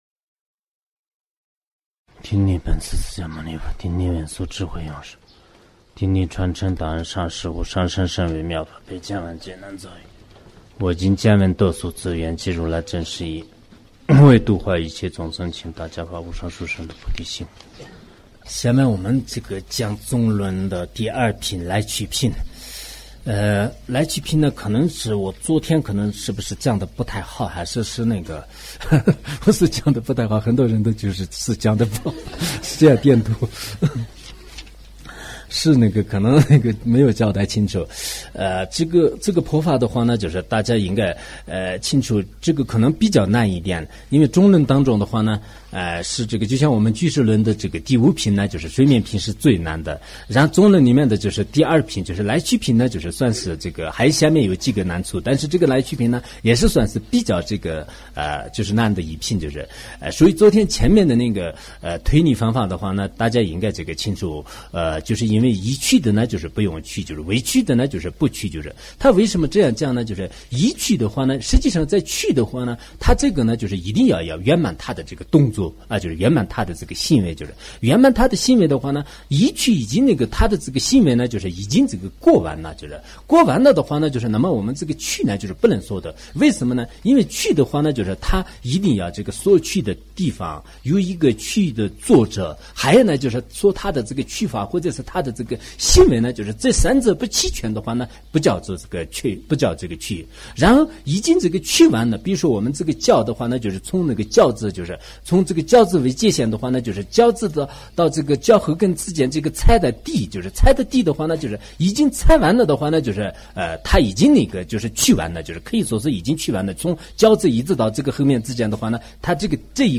中论讲解 龙树菩萨 ·造论 麦彭仁波切 · 注释 索达吉堪布 ·译讲 顶礼本师释迦